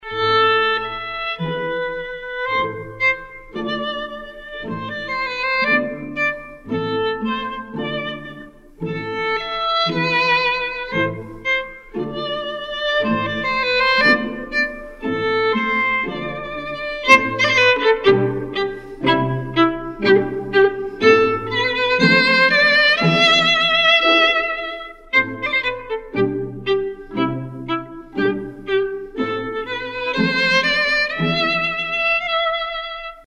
Категория: Классические | Дата: 06.12.2012|